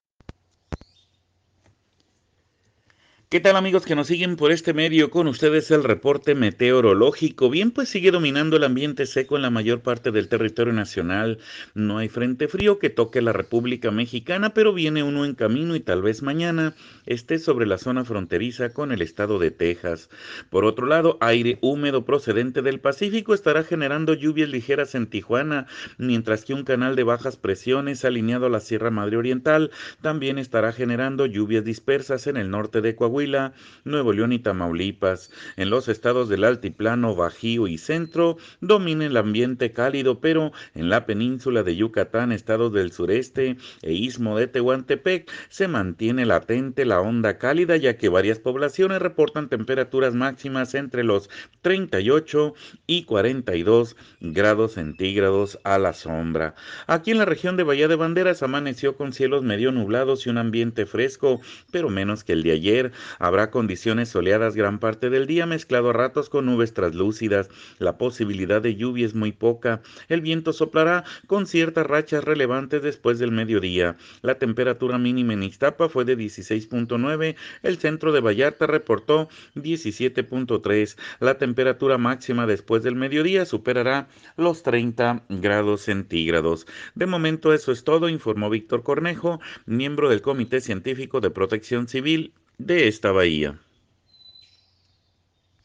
escuche al meteorólogo